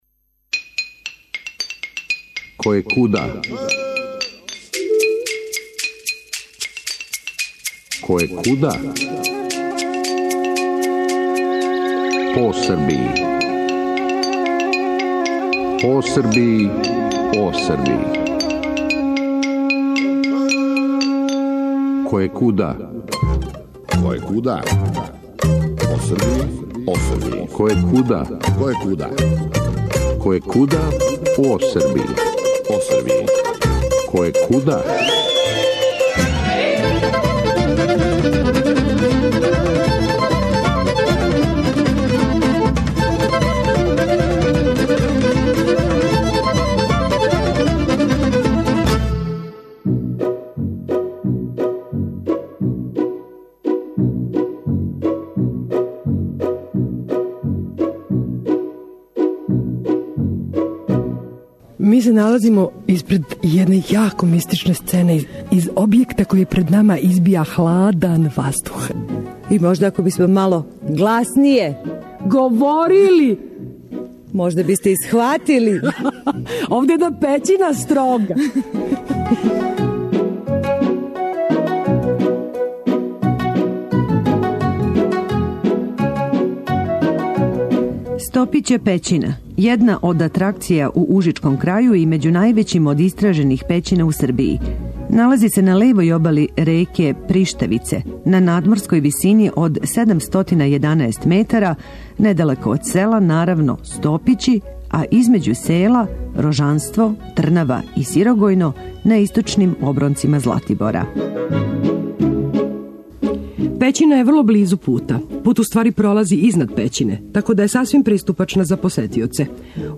Којекуда ове недеље у Стопића пећини на Златибору. Ова пећина је једна од атракција у ужичком крају и међу највећим од истражених пећина у Србији.